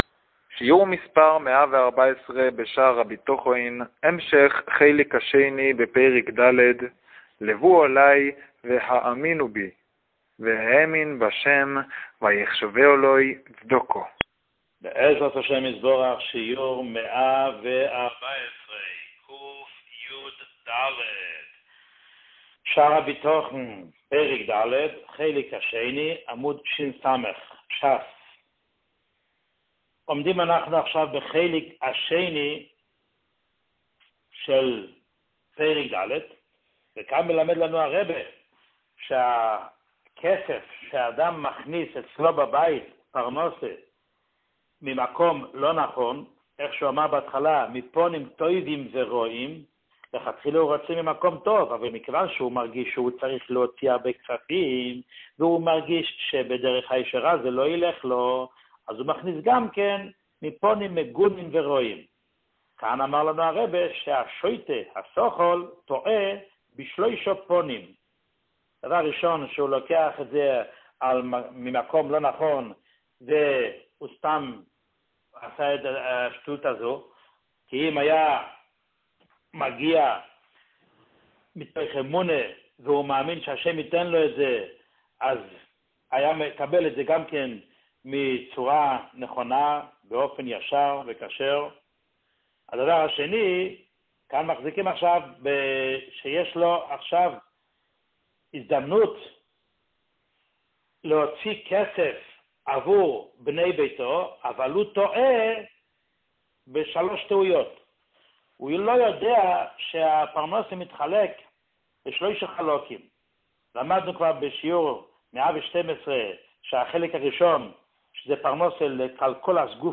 שיעור 114